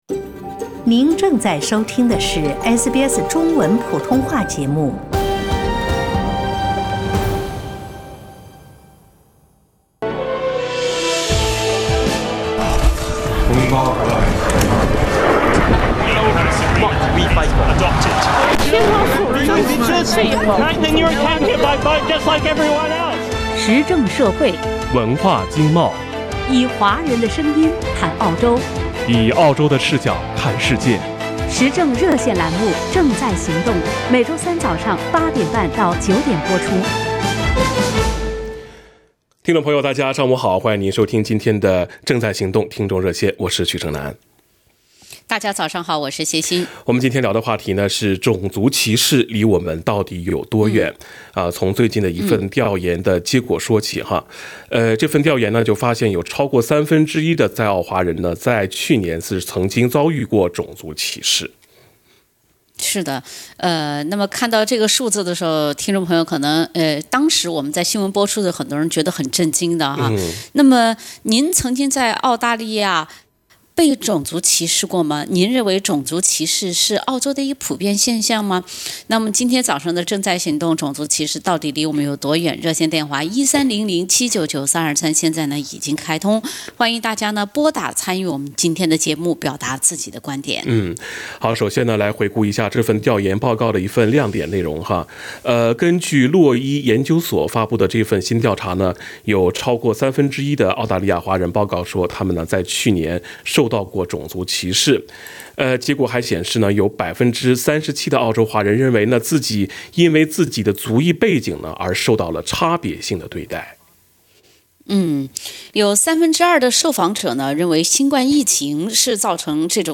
国家信息服务处的功能： 为您提供有关联邦人权和反歧视法规定的权利和责任的信息 讨论您是否可以向委员会投诉，解释法律对个案的适用性 向您提供有关如何提出投诉、回应投诉或处理具体歧视问题的信息 将您介绍给其他可能帮助您的组织 时政热线节目《正在行动》逢周三上午8点20